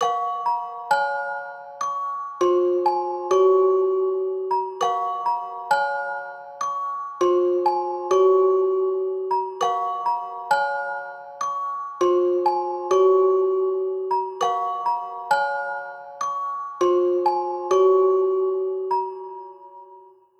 Вложения 09_PopMarimba_100bpm_a#.wav 09_PopMarimba_100bpm_a#.wav 5,2 MB · Просмотры: 121